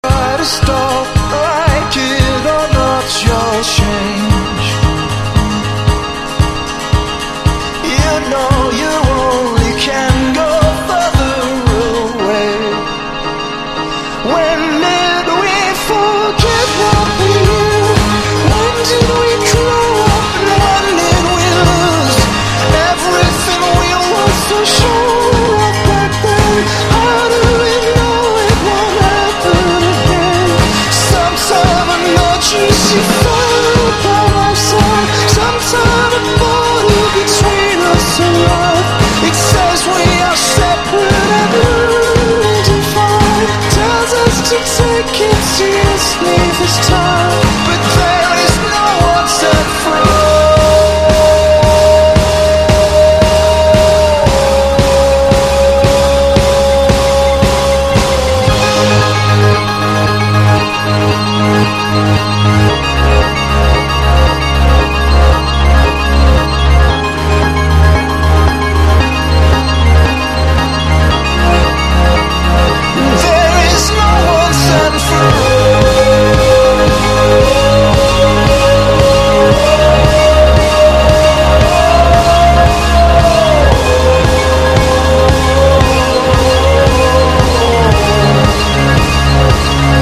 1. 90'S ROCK >
NEO ACOUSTIC / GUITAR POP